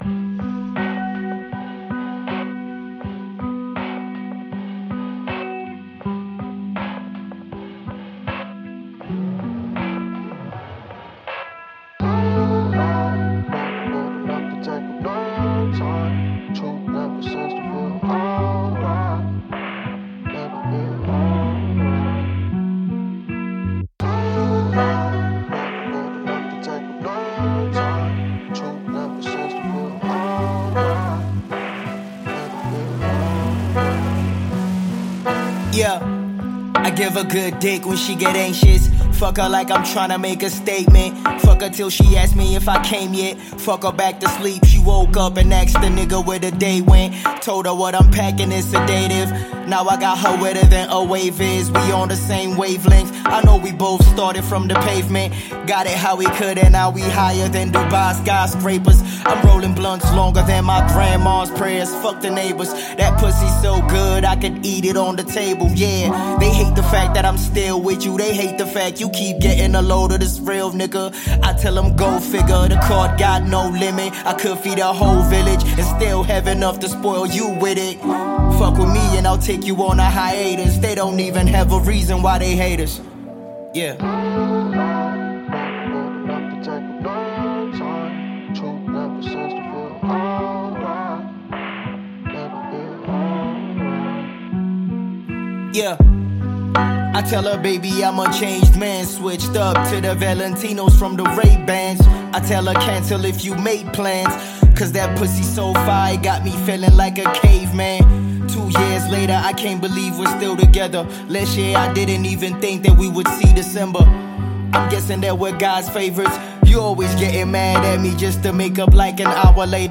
South African rapper